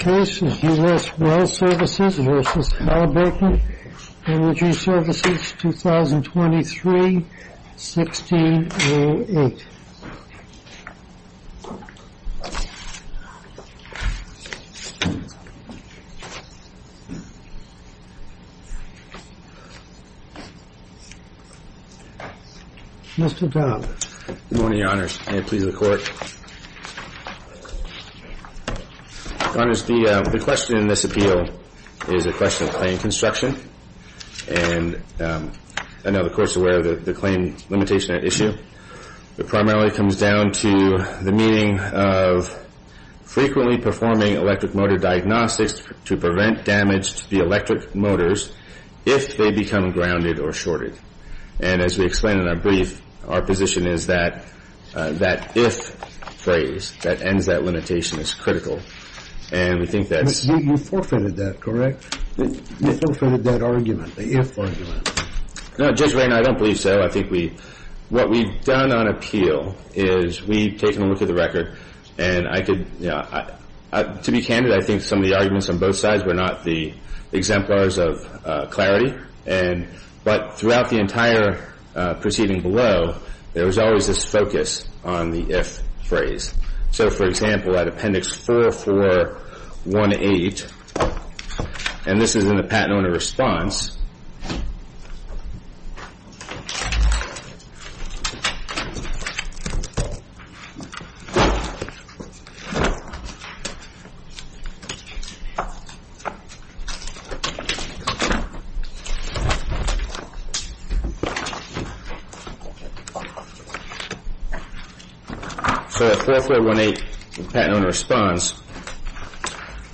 A chronological podcast of oral arguments with improved files and meta data.